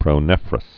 (prō-nĕfrəs, -rŏs)